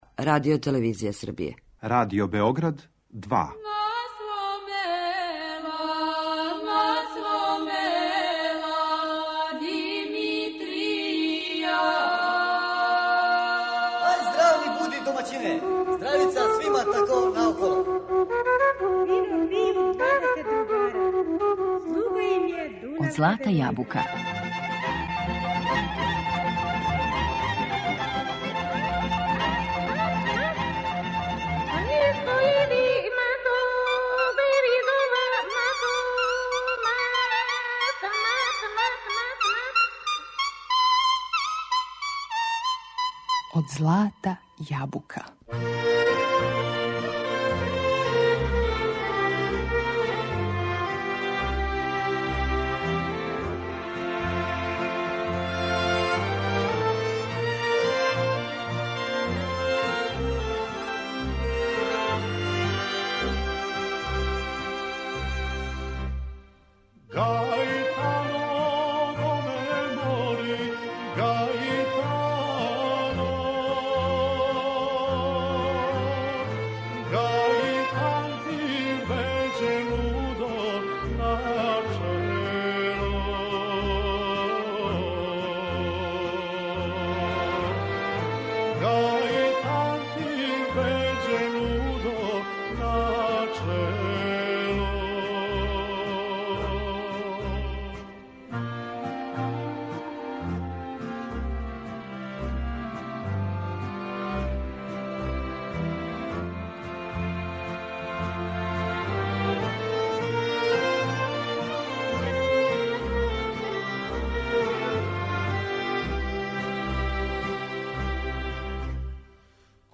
Каријера дуга пет деценија посвећена је провереној народној песми. У свом репертоару негује врањске, косовске, македонске и шумадијске песме које су готово пале у заборав.